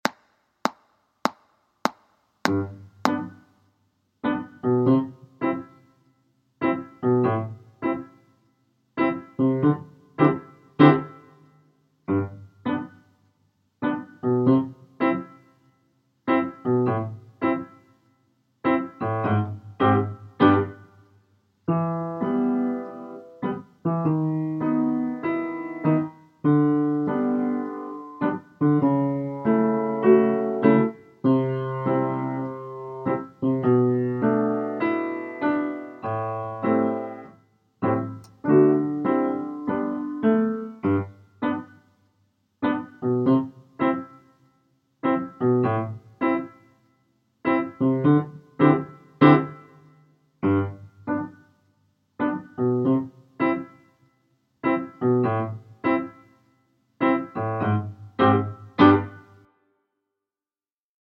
Hot Potato Piano Backing